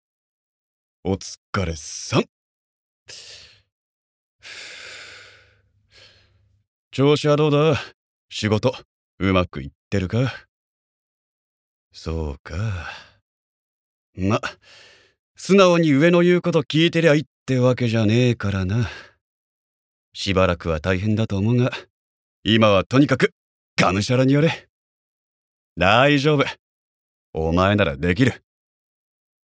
ボイスサンプル
セリフ５